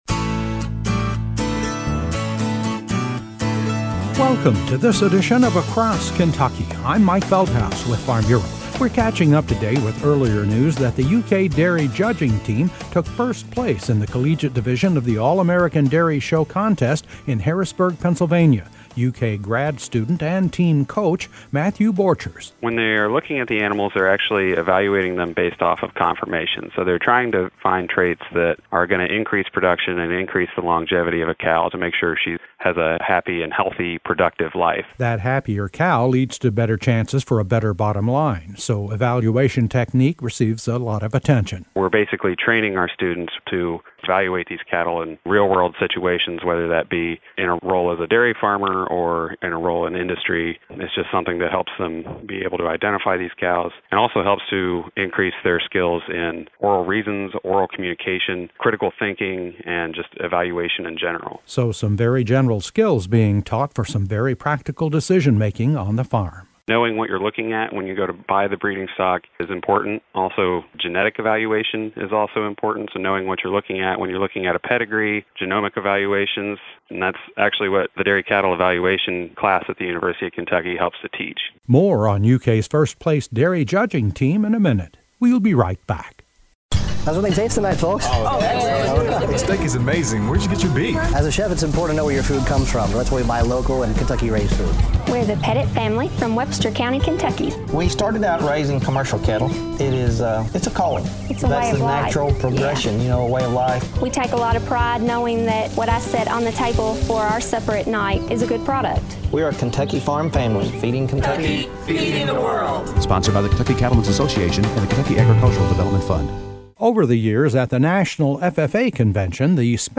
A feature report on the UK Dairy Judging team which recently took first place honors in the collegiate division of the All-American Dairy Show contest in Harrisburg, Pennsylvania.&nbsp